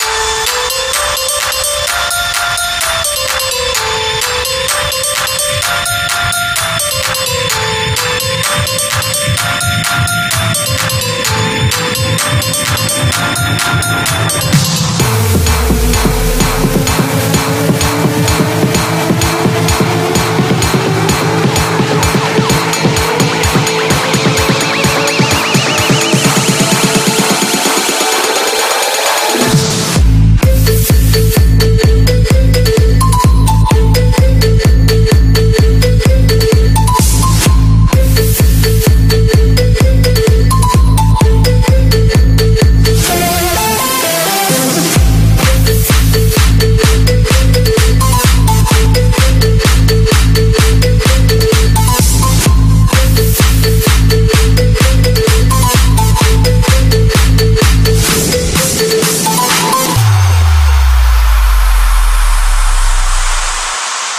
• Качество: 144, Stereo
Техно колокольчики)